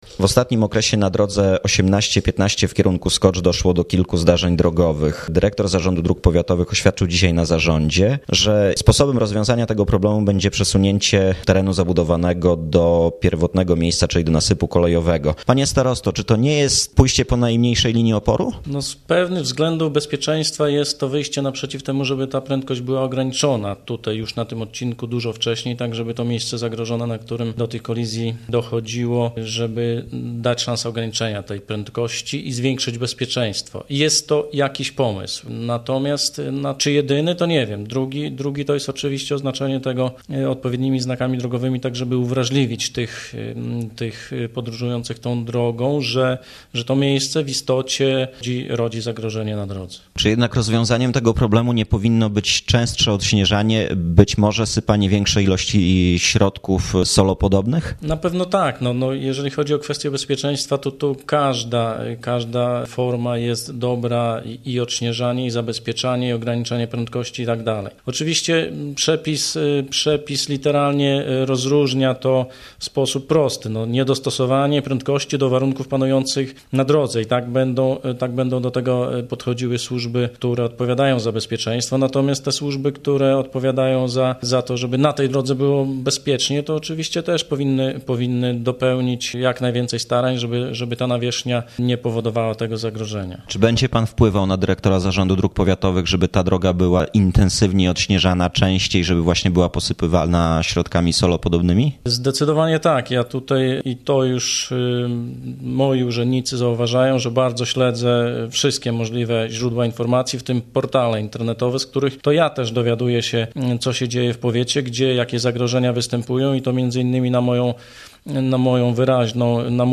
rozmowa z Andrzejem Ciołkiem, starostą gołdapskim